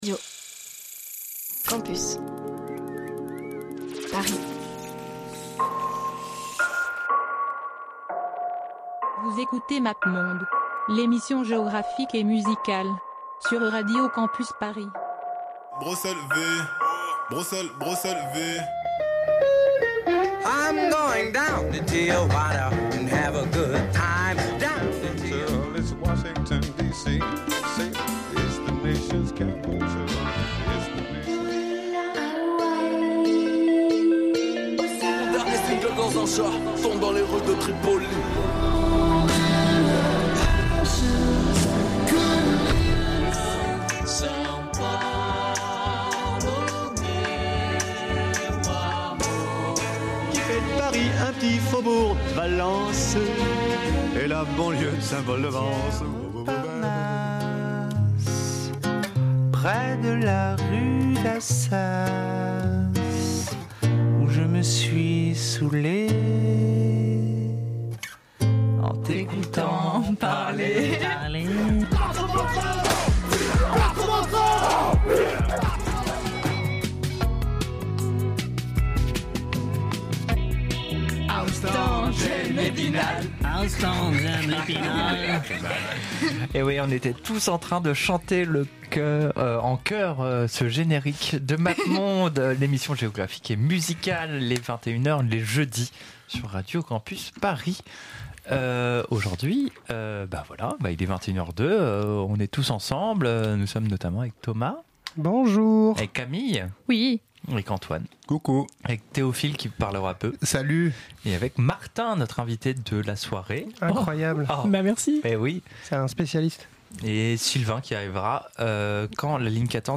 La musique de la diagonale du vide
Au programme : des énormes stars de la variété française, de la musique traditionnelle, des chansons tristes, du post-punk, du chauvinisme et du screamo.